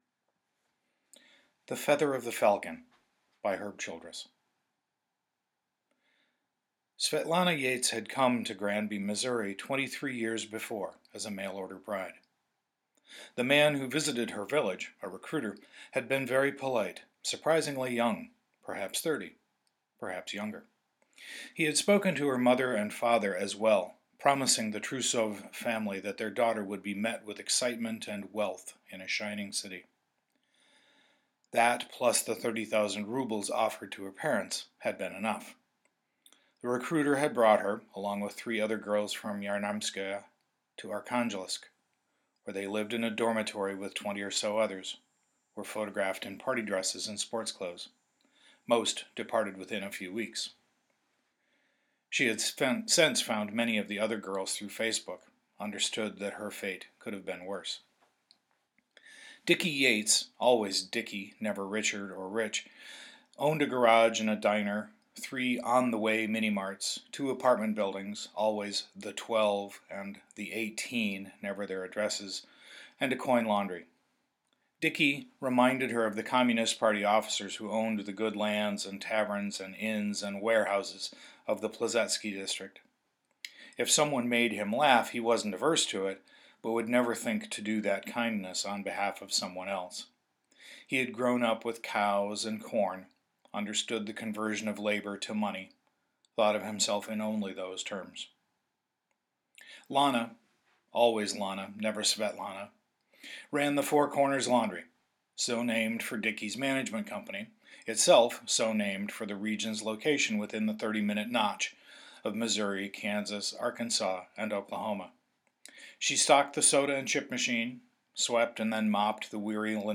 I’m putting it up here as an audio story.